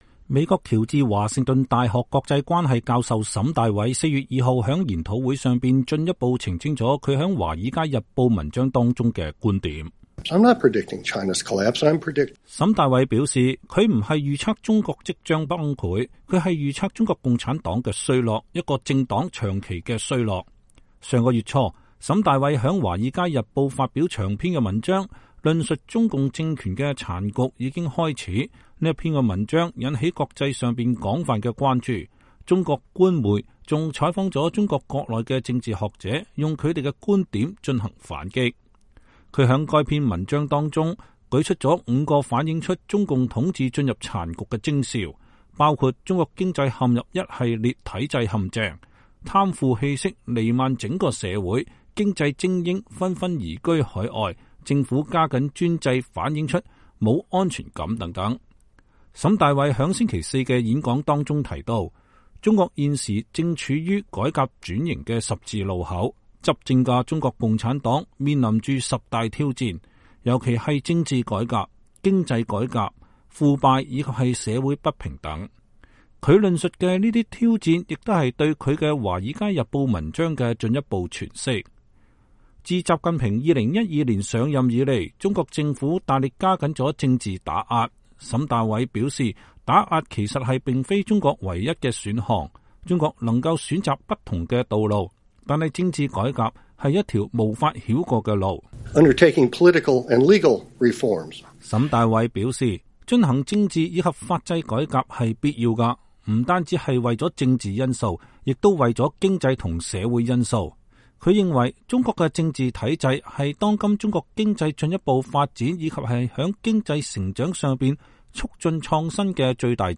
美國喬治華盛頓大學國際關係教授沈大偉（ DAVID SHANBAUGH ） 4 月 2 號在研討會上進一步澄清了他在華爾街日報文章中的觀點。